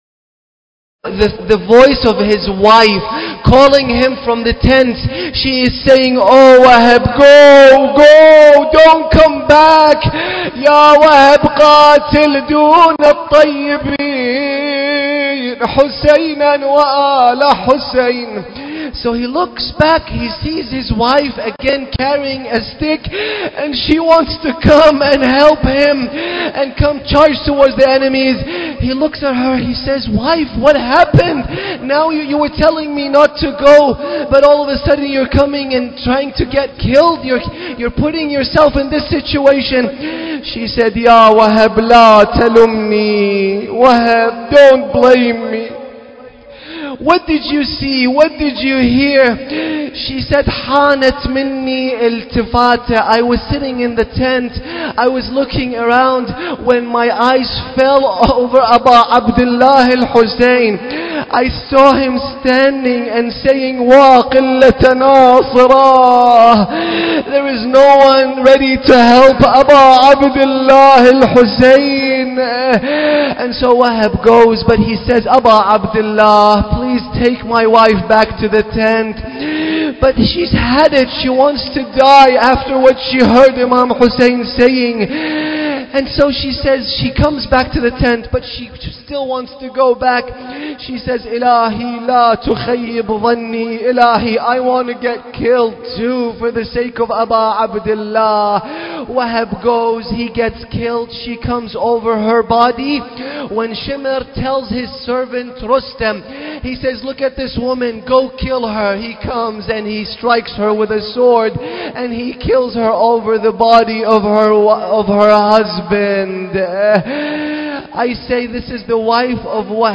ملف صوتی لیلة 6 محرم بصوت باسم الكربلائي